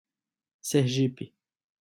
Sergipe (Brazilian Portuguese: [sɛʁˈʒipi]
Pt-br_Sergipe.ogg.mp3